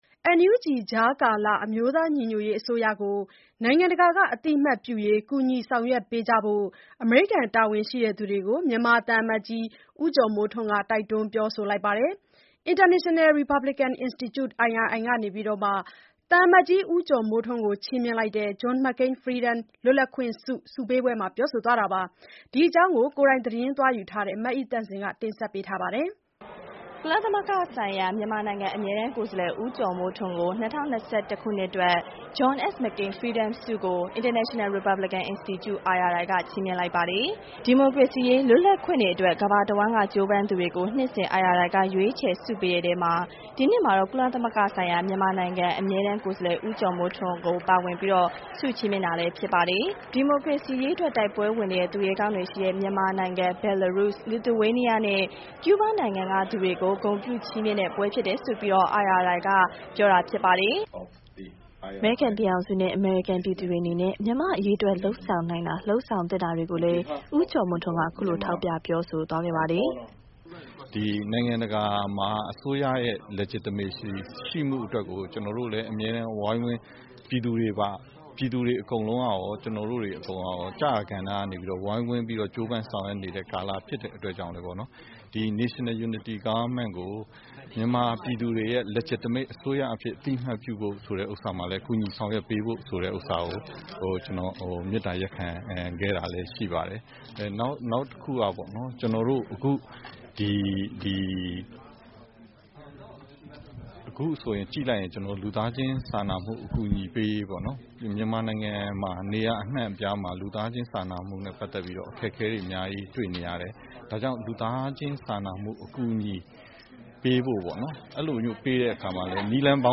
NUG ကွားကာလအအမြိုးသားညီညှတျရေးအစိုးရကို နိုငျငံတကာကအသိအမှတျပွုရေး ကူညီဆောငျရှကျပေးဖို့ အမရေိကနျတာဝနျရှိသူတှကေို မွနျမာသံအမတျကွီး ဦးကြောျမိုးထှနျးက တိုကျတှနျးပွောဆိုပါတယျ။ International Republican Institute IRI က သံအမတျကွီးဦးကြောျမိုးထှနျးကိုခြီးမွှင့ျလိုကျတဲ့ John S.McCaing Freedom လှတျလပျခှင့ျဆု ဆုပေးပှဲမှာ ပွောဆိုသှားတာပါ။